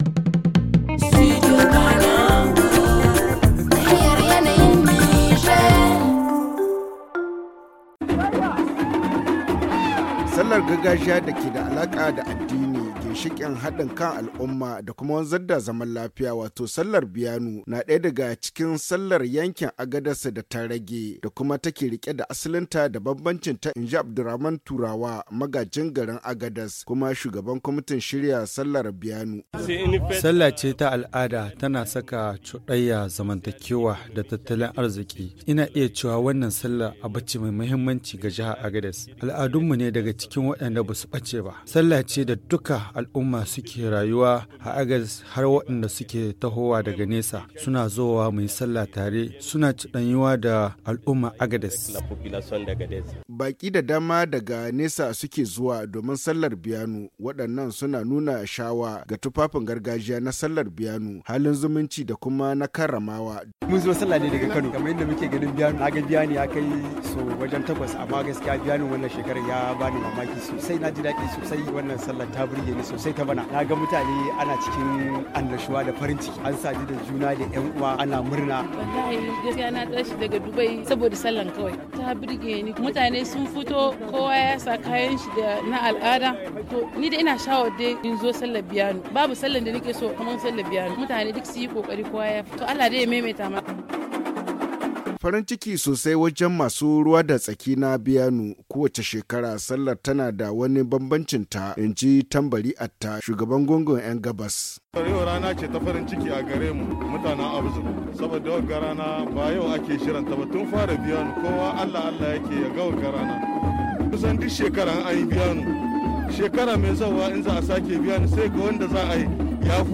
Le magazine en haoussa